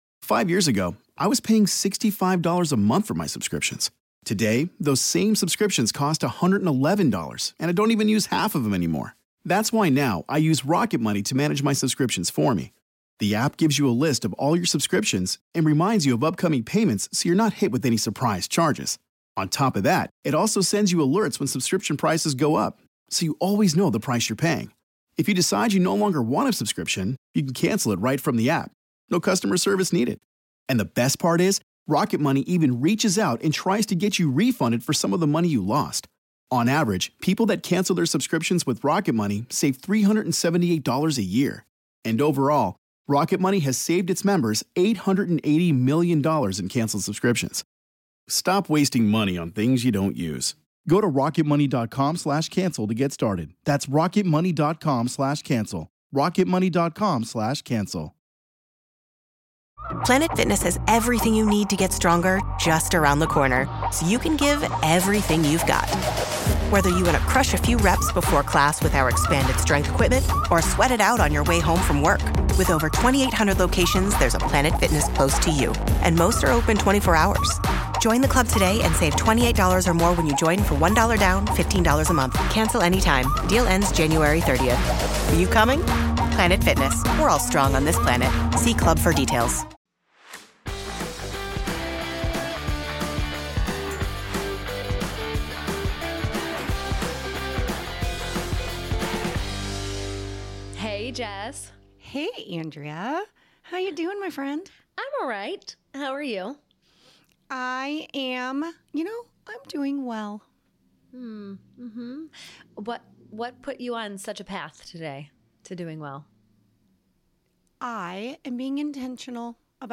Disclaimer: Please note while this podcast features two therapists, and may feel very therapeutic, this is not therapy!